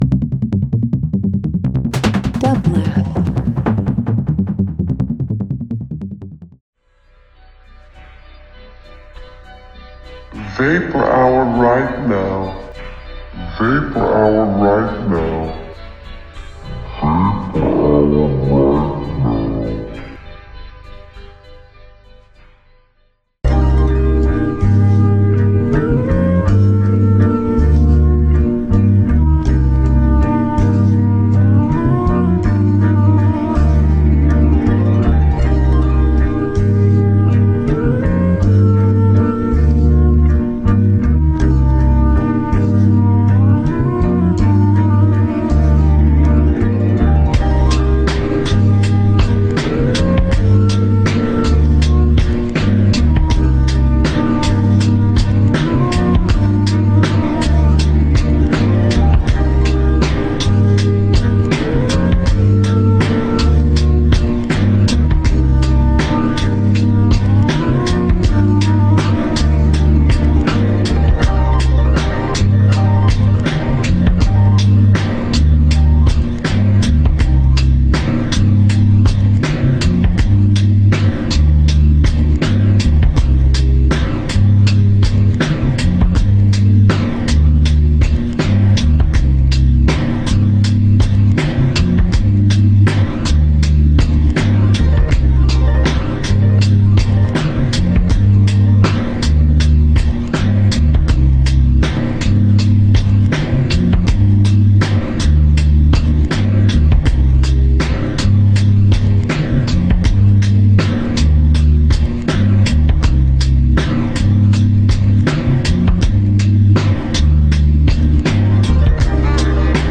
Downtempo Electronic Synth vaporwave